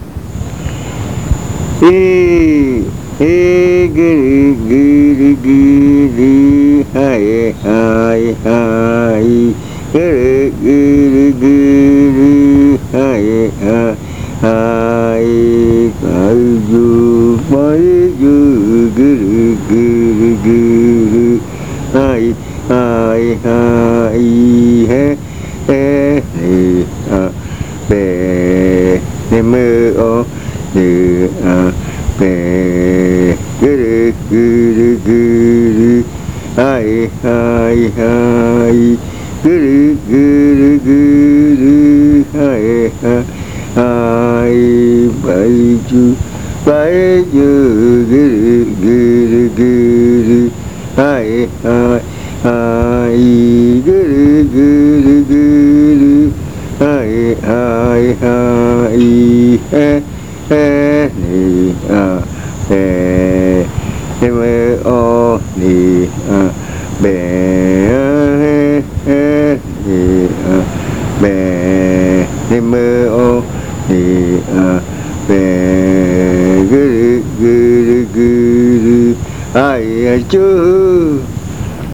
Leticia, Amazonas
Canto del ritual de espantar los malos espíritus, se canta en la noche siguiente del baile.
This chant is sung between 8:00 and 12:00 at night. Chant of the ritual to scare away evil spirits, it is sung on the night after the dance.